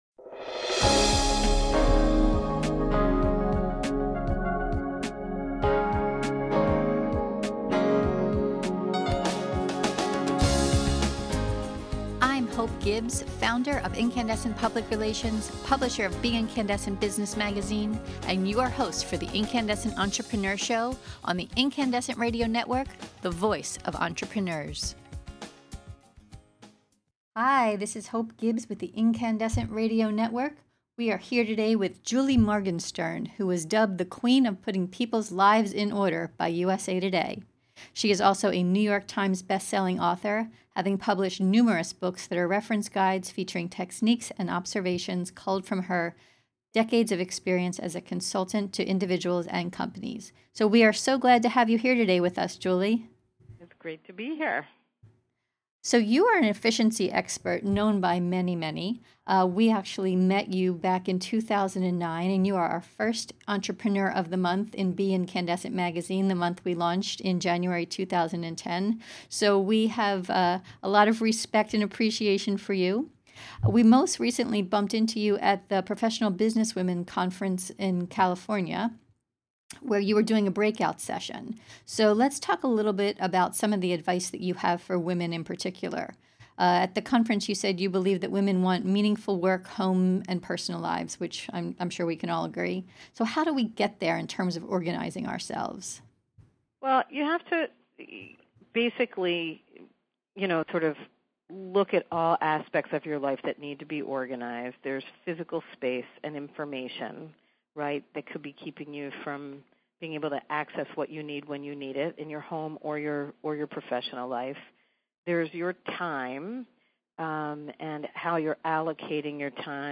In this podcast interview you’ll learn: Julie firmly believes that women want meaningful work, home, personal lives.